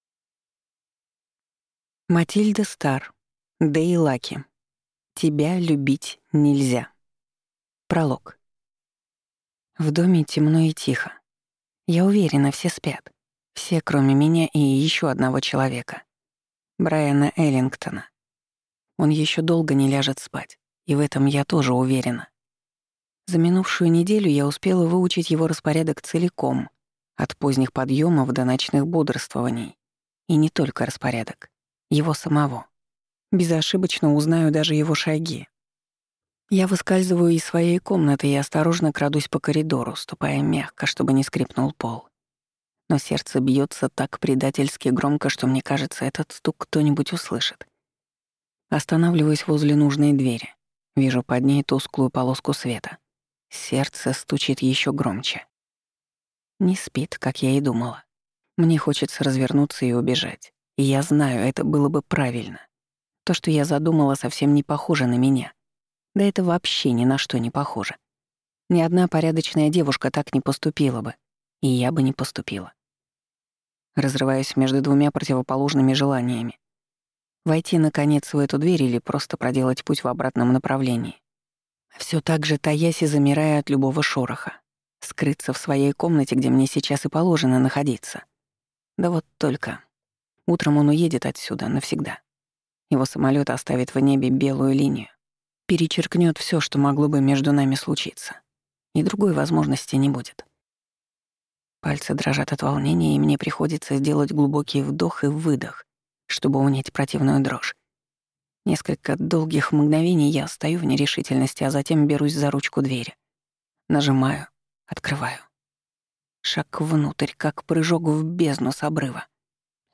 Аудиокнига Тебя любить нельзя | Библиотека аудиокниг